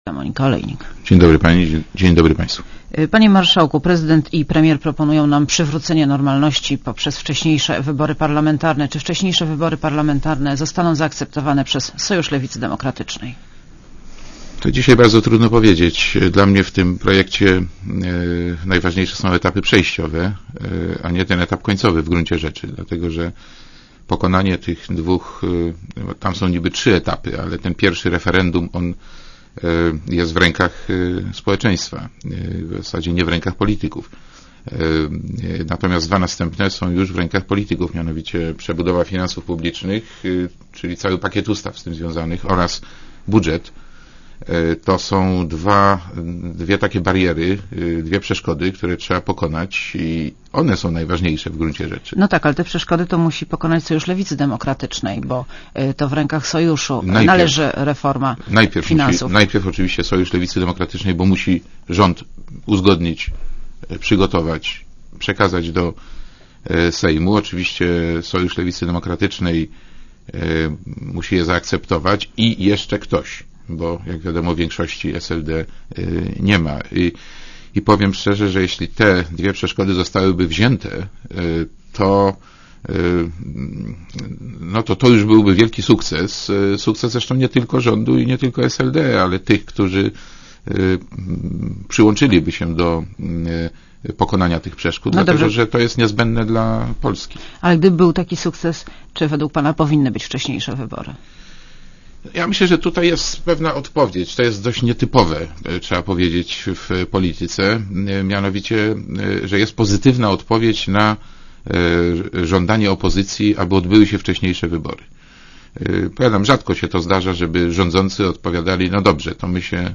Monika Olejnik rozmawia z marszałkiem Sejmu Markiem Borowskim